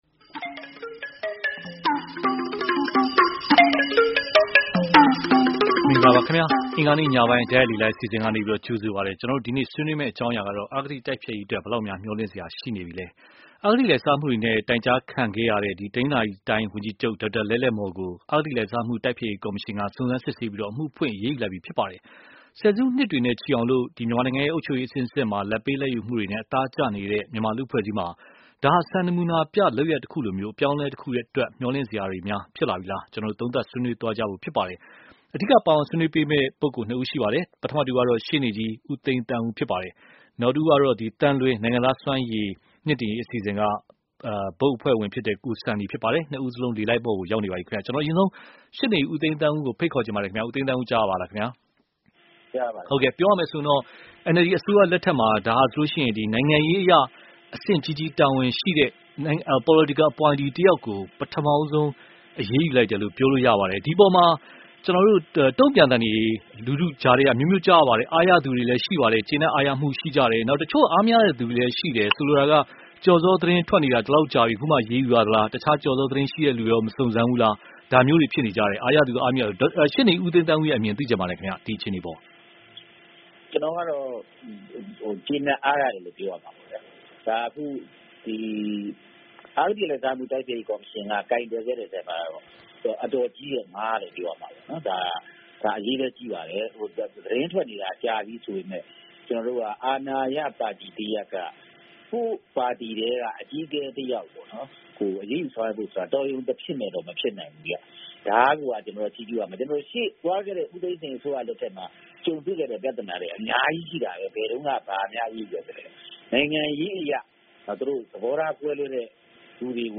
အဂတိလိုက်စားမှုတိုက်ဖျက်ရေးမျှော်လင့်စရာ (တိုက်ရိုက်လေလှိုင်း)